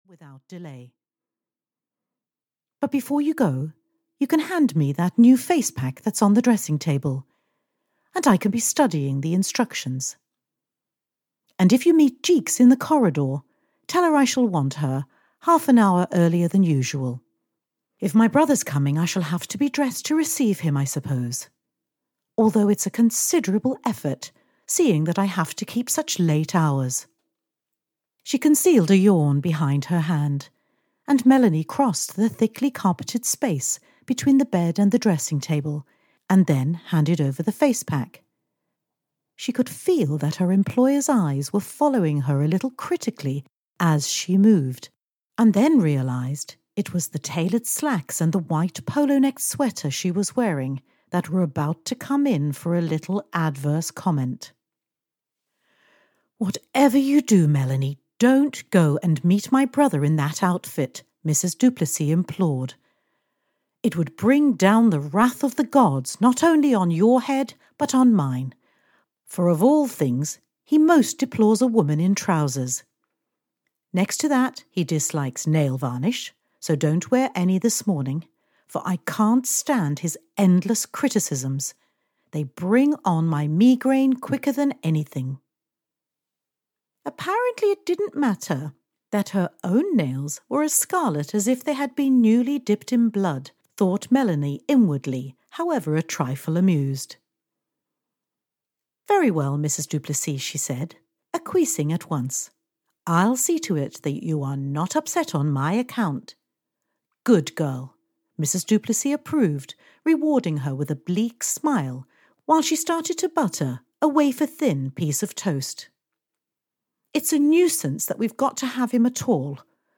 Audiobook The gates of dawn written by Susan Barrie.
Ukázka z knihy